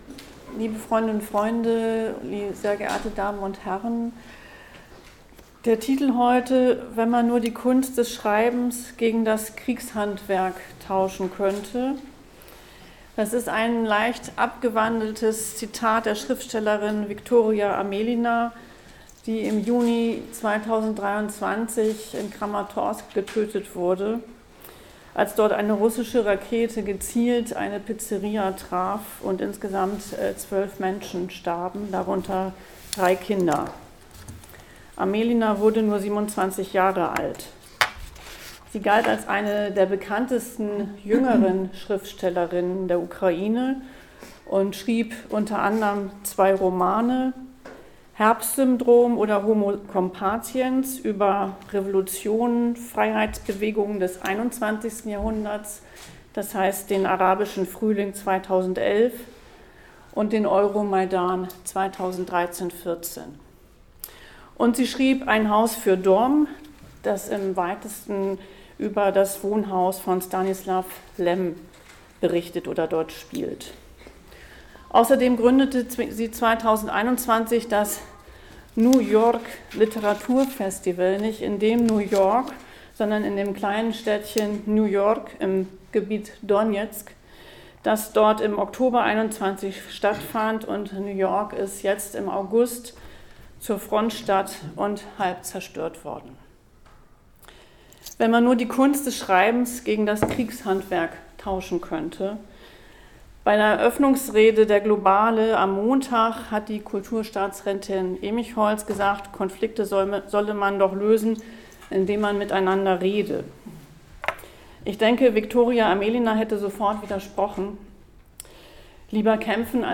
Mitschnitt der Veranstaltung: